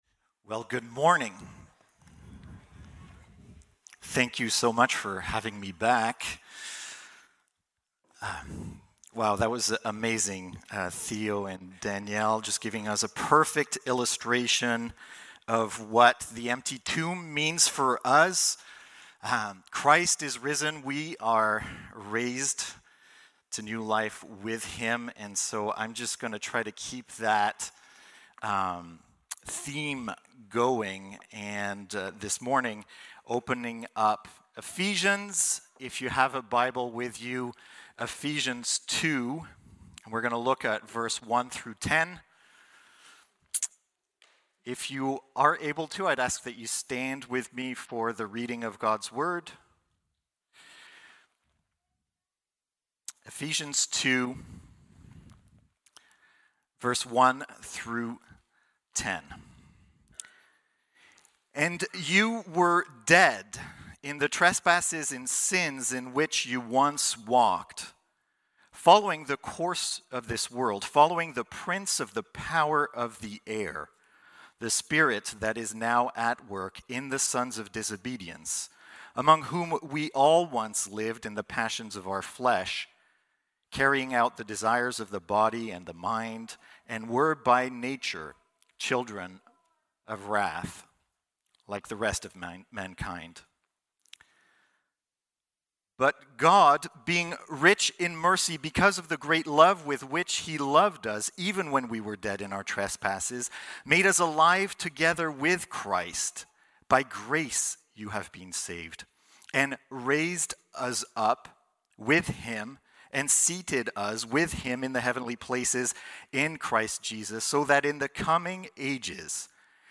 Easter Sunday Service